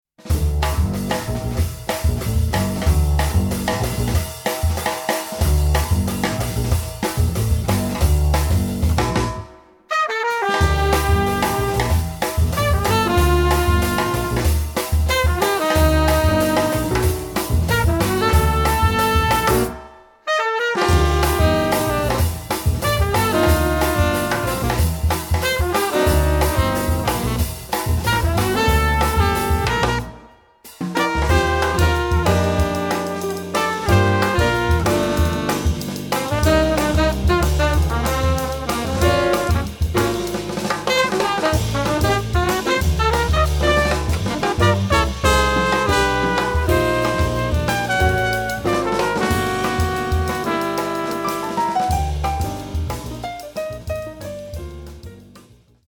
saxophone
trumpet
piano
double bass
drums